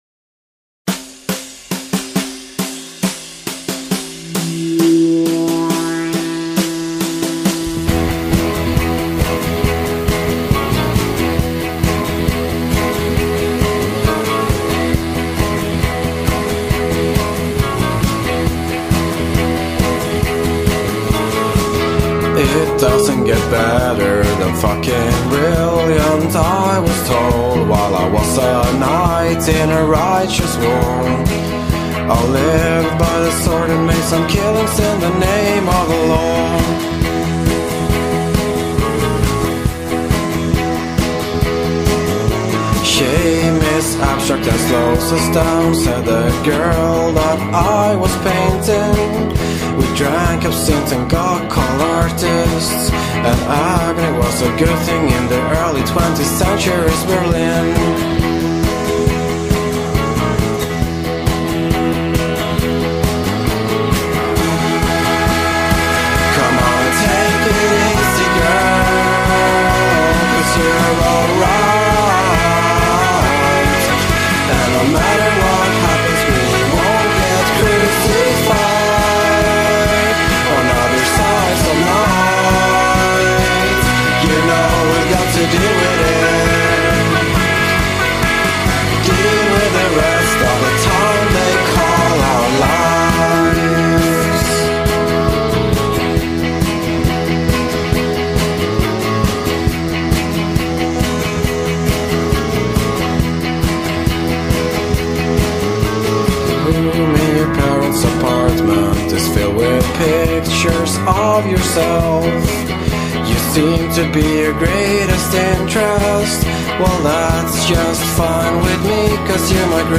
They seem to be a five-piece band.